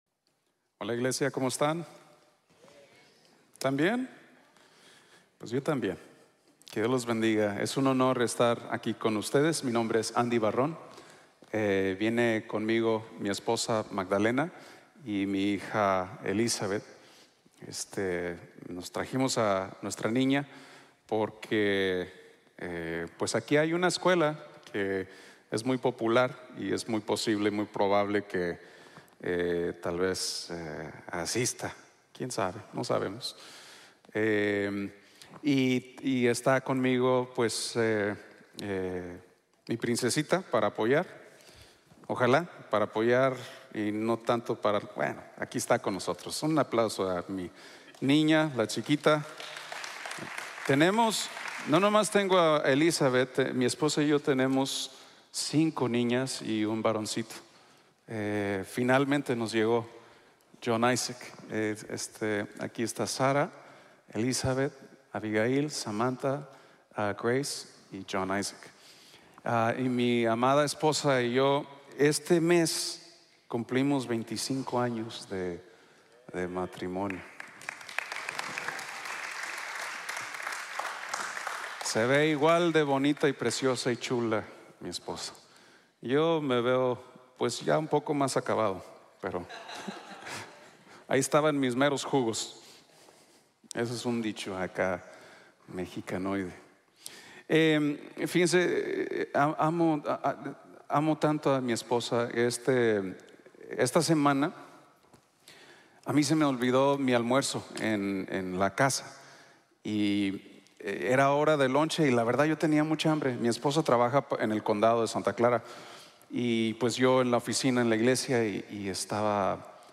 El no amar a la gente es pura religion | Sermon | Grace Bible Church